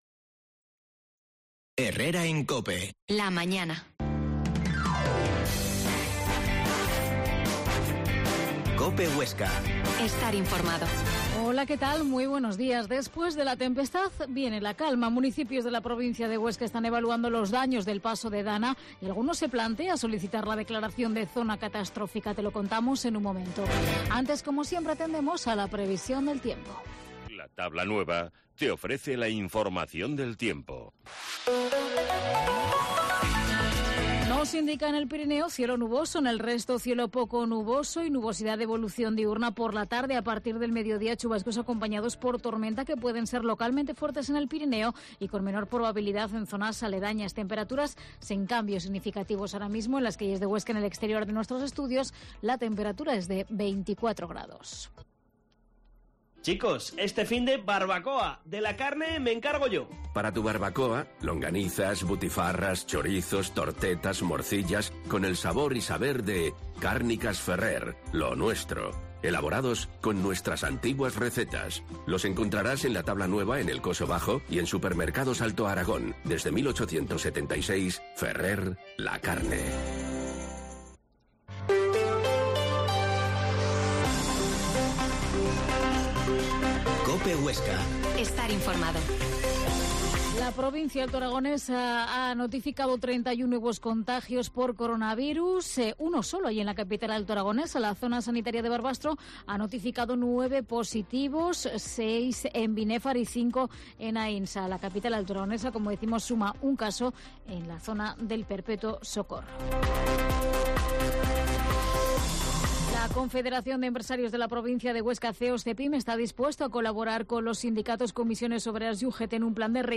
Herrera en Cope Huesca 12,50h. Entrevista al alcalde de Sena